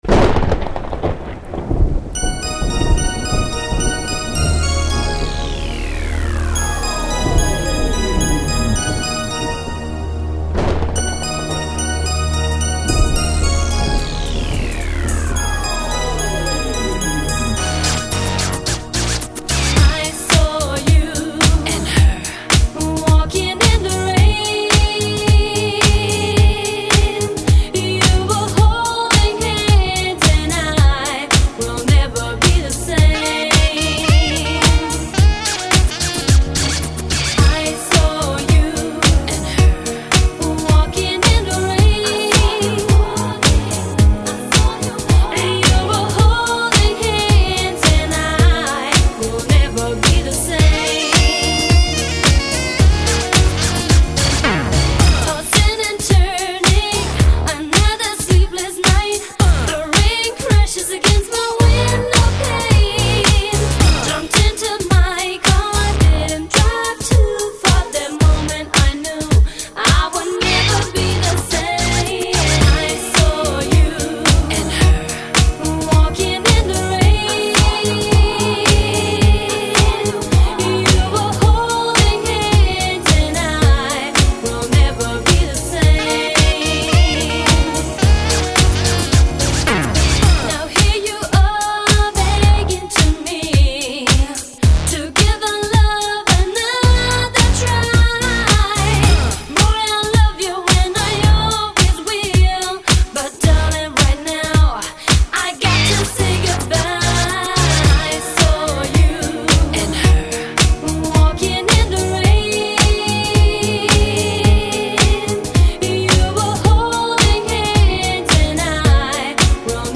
音乐风格: 慢摇
唱的，感觉翻唱比原版好多了，主要是因为这个翻唱版本加上了许多现代的电子音乐，以前的那个版本是没有的。
和大多的DJ音乐不同的是，你会听出忧伤的味道。